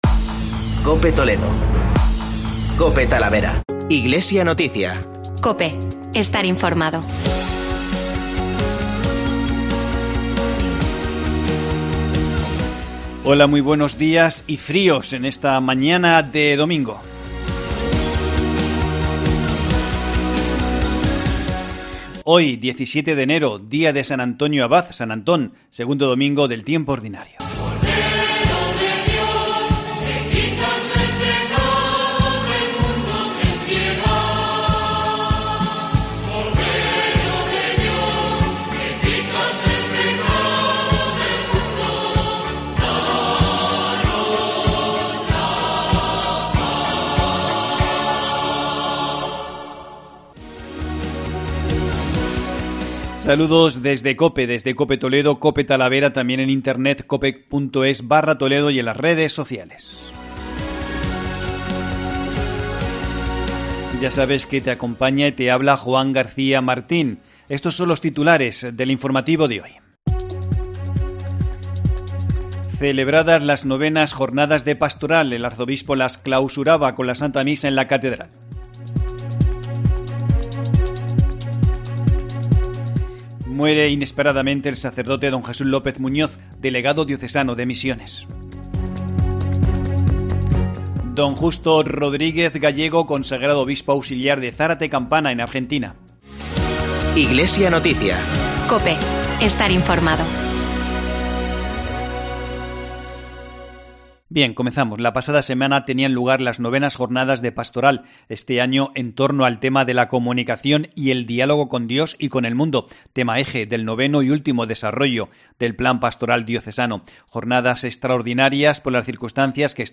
Informativo Diocesano Iglesia en Toledo